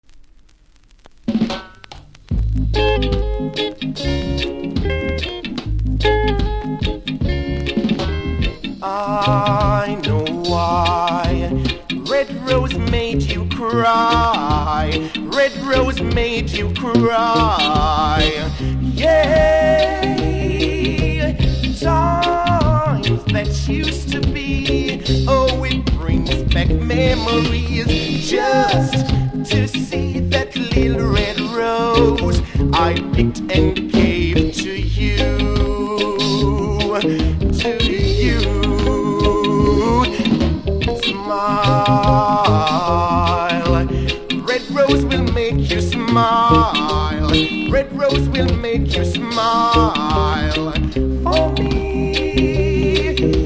キズ多めですがそれほど音には影響してないと思いますので試聴で確認下さい。